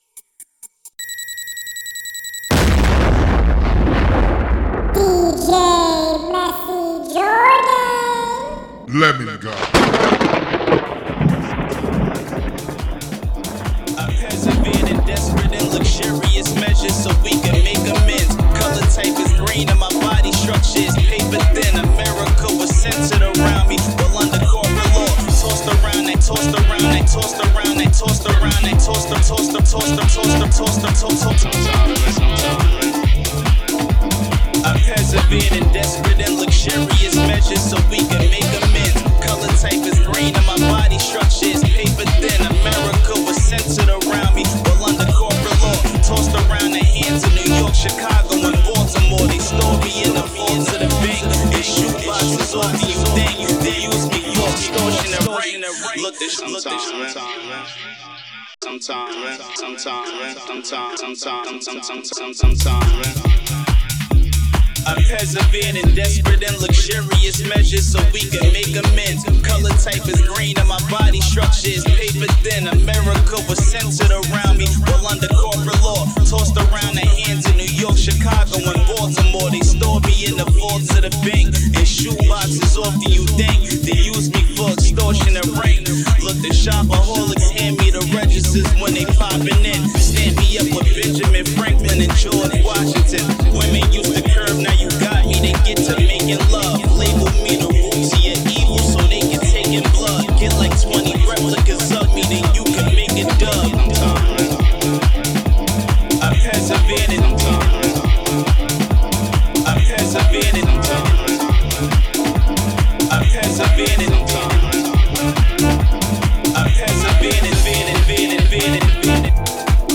Genre: EDM.